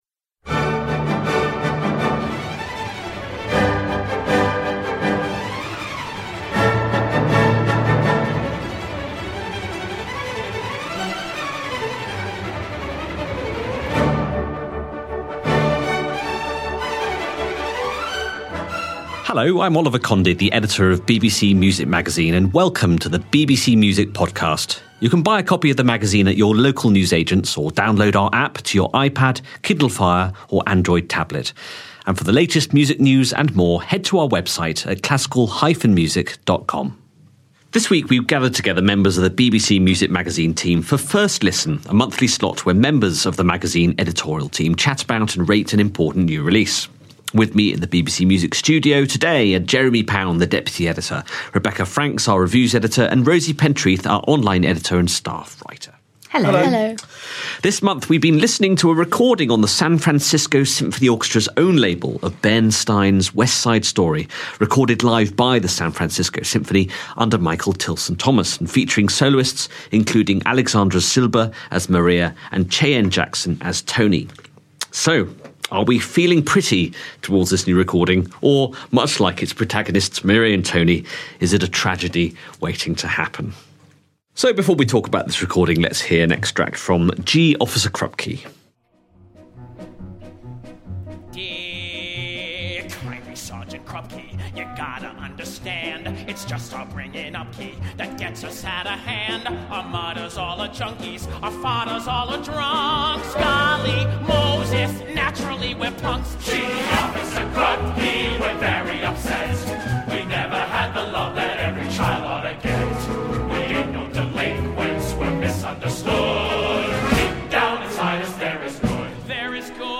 How does a complete live concert recording of Bernstein's West Side Story shape up? The BBC Music Magazine team share their thoughts on the San Francisco Symphony Orchestra's new CD.